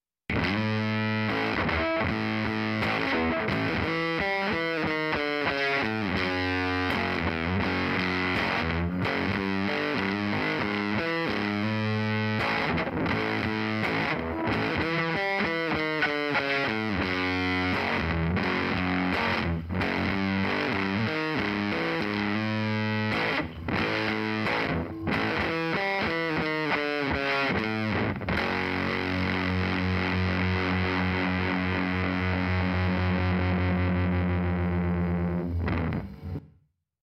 Electric Guitar (fuzz) - LCT 440 PURE